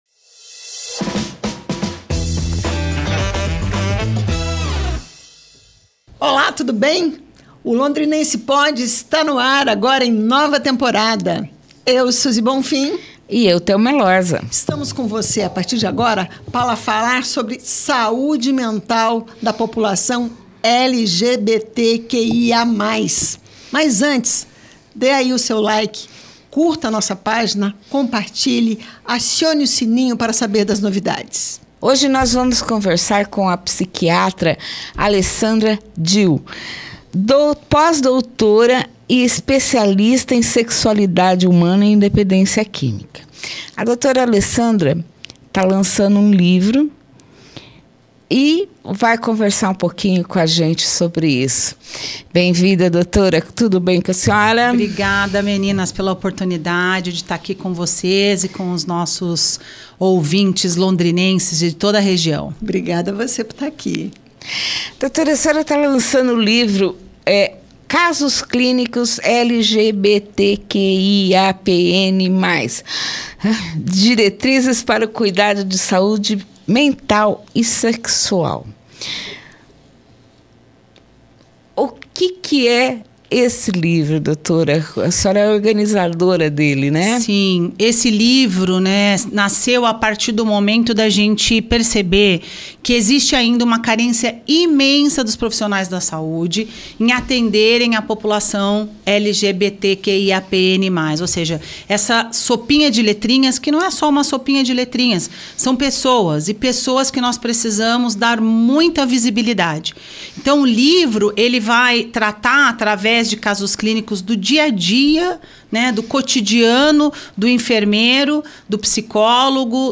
O Londrinense Pod entrevista a pós-doutora em psiquiatria